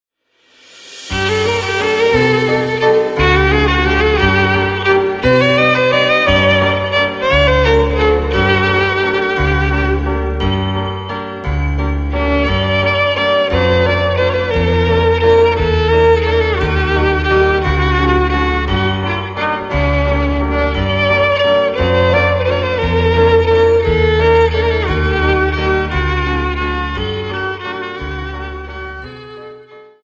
Viennese Waltz 59 Song